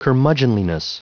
Prononciation du mot curmudgeonliness en anglais (fichier audio)
curmudgeonliness.wav